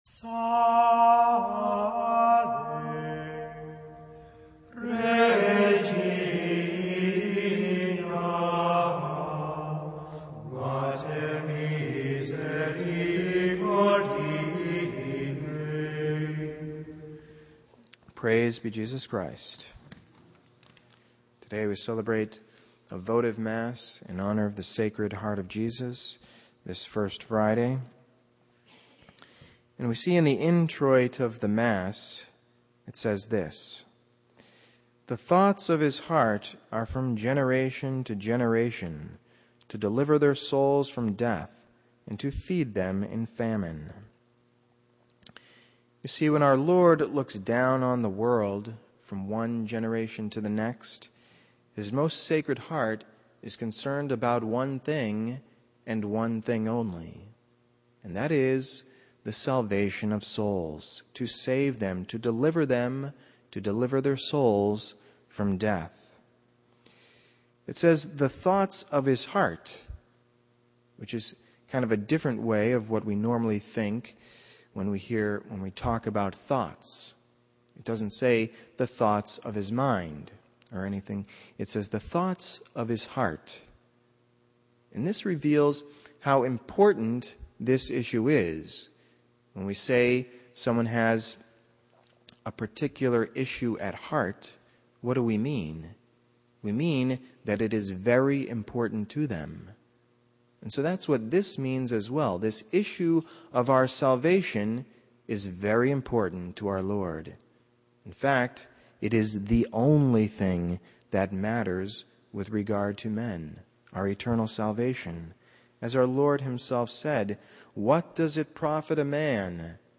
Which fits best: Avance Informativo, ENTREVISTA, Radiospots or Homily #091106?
Homily #091106